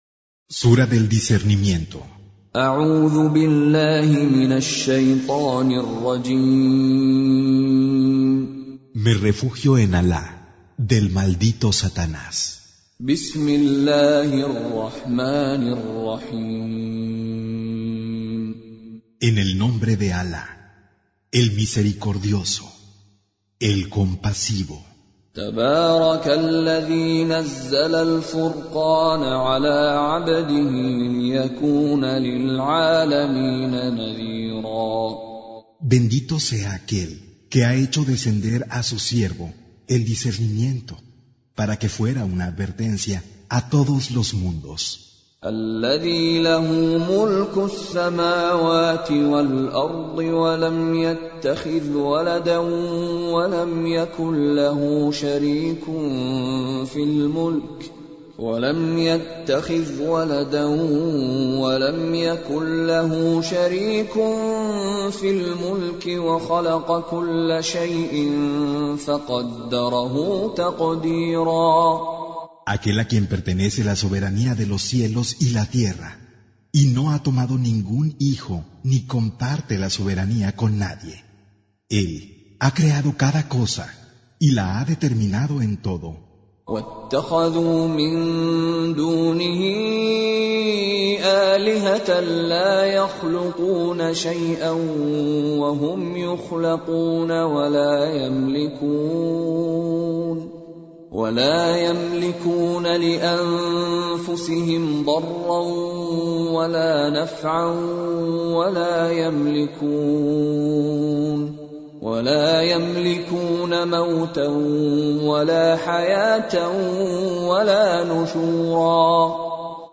Traducción al español del Sagrado Corán - Con Reciter Mishary Alafasi
Surah Sequence تتابع السورة Download Surah حمّل السورة Reciting Mutarjamah Translation Audio for 25. Surah Al-Furq�n سورة الفرقان N.B *Surah Includes Al-Basmalah Reciters Sequents تتابع التلاوات Reciters Repeats تكرار التلاوات